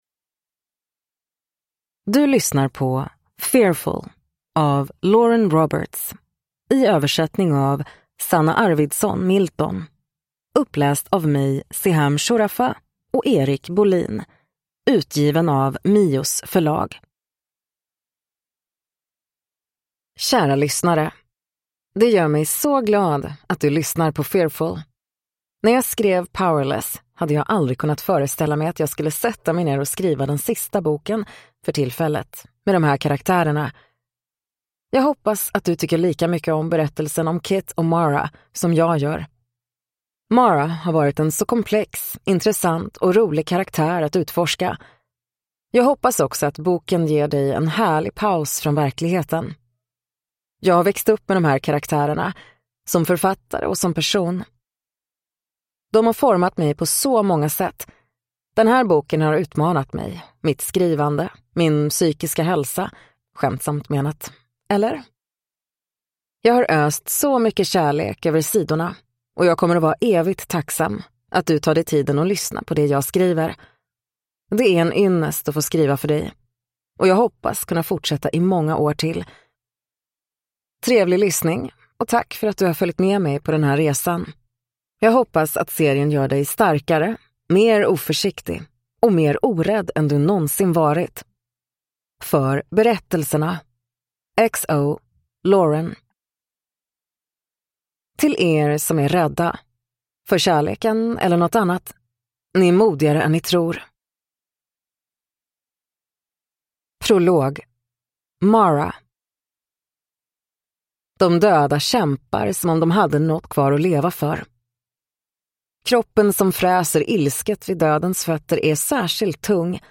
Fearful (svensk utgåva) – Ljudbok